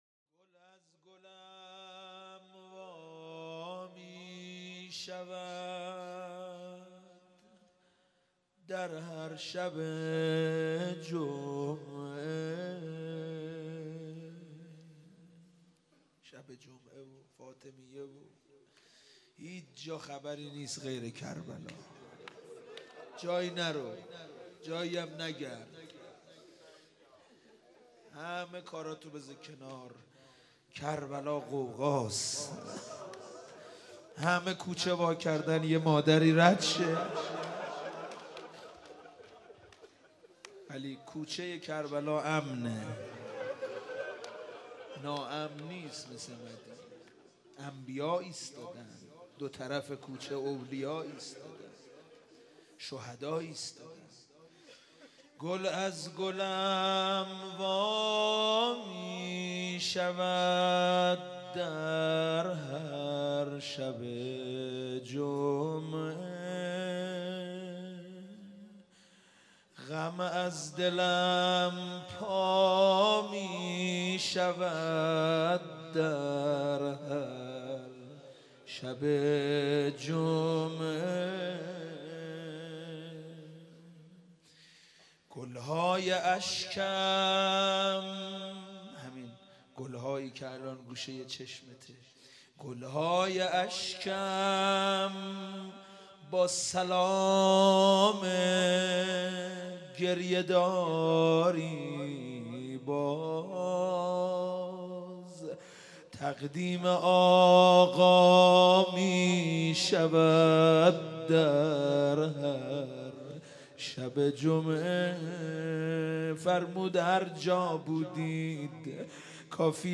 مناجات - گل از گلم وا میشود